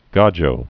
(gäjō)